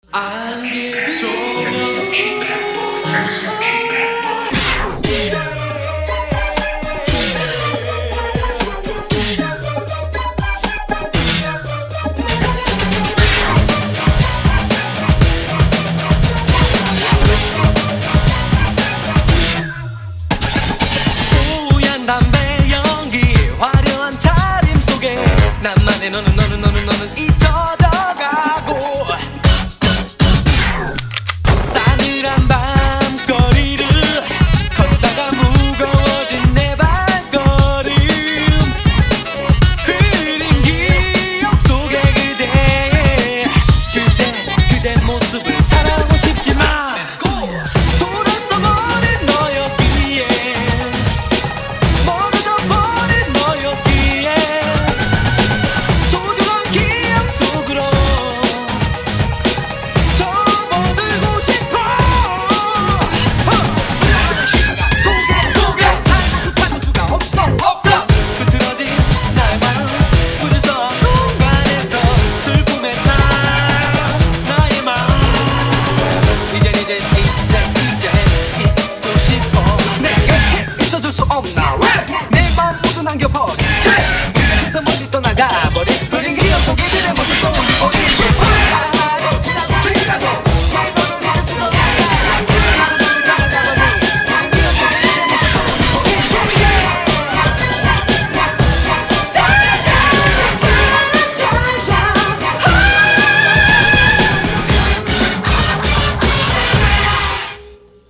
Audio QualityPerfect (High Quality)
BPM: 118
Genre: K-Pop